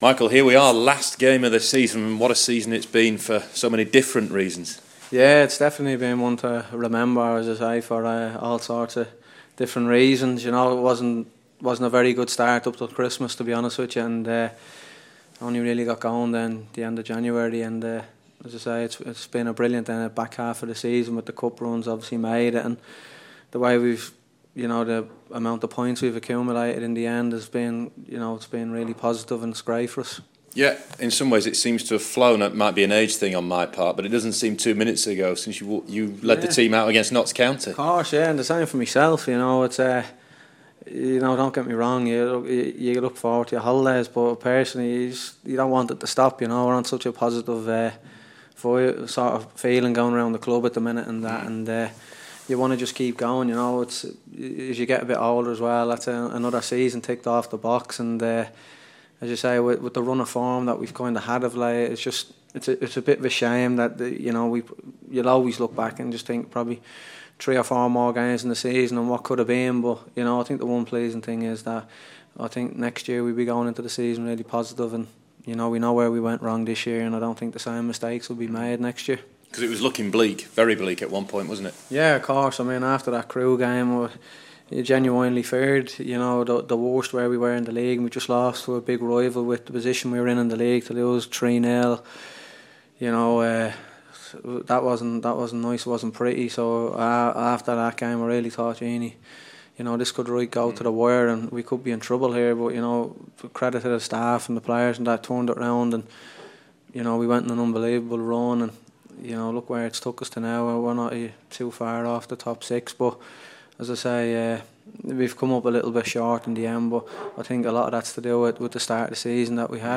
Sheffield United captain Michael Doyle ahead of the final game of the season